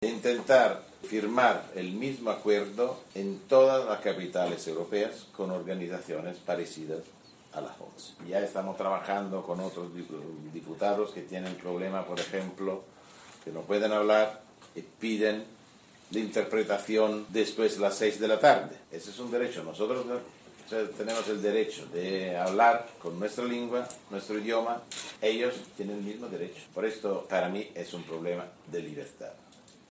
señaló Tajani formato MP3 audio(0,23 MB).